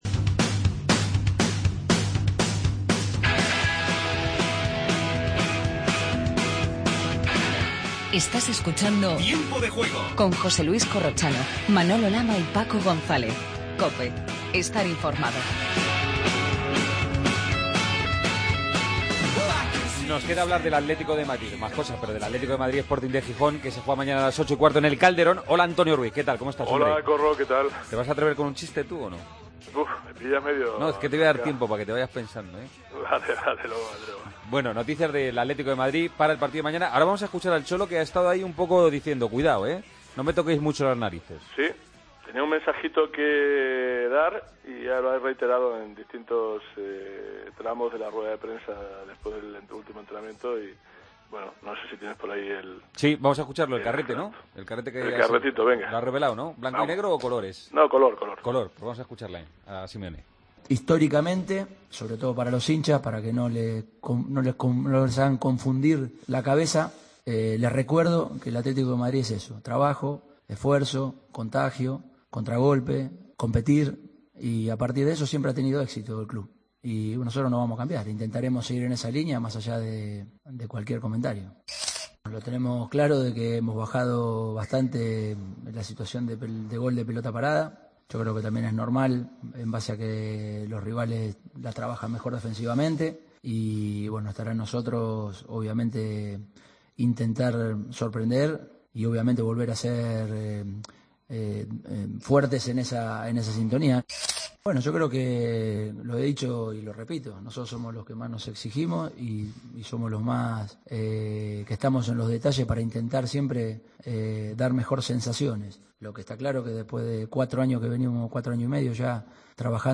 Redacción digital Madrid - Publicado el 08 nov 2015, 01:42 - Actualizado 19 mar 2023, 03:29 1 min lectura Descargar Facebook Twitter Whatsapp Telegram Enviar por email Copiar enlace Seguimos con la última hora del Atlético – Sporting y Athletic – Espanyol. Escuchamos a los protagonistas del sábado: Javi Guerra, Sergi Enrich y Lucas Pérez.